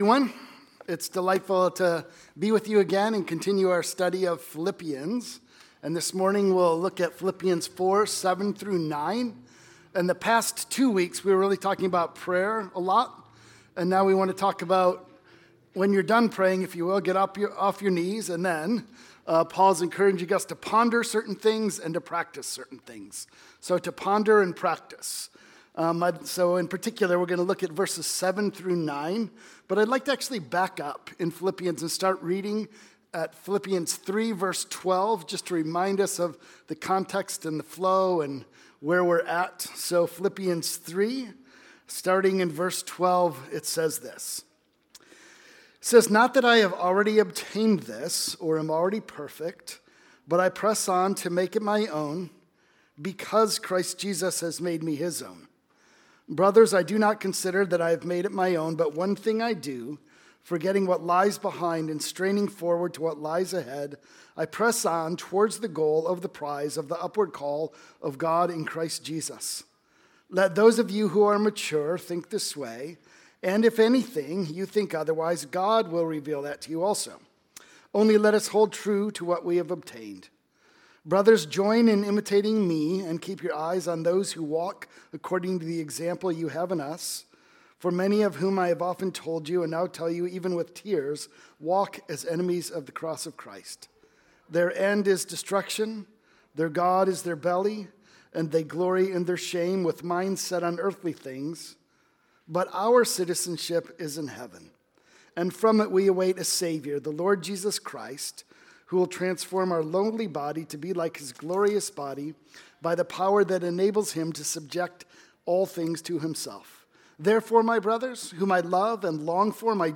Lectures taught at CURC